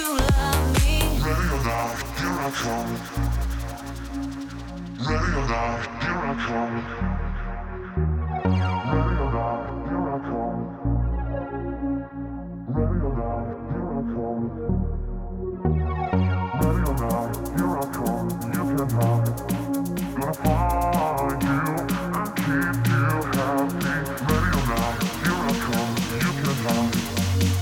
Кроме опускания на 6 полутонов и катофф что слышно? Какой-то автотьюн вокодер с фленжером?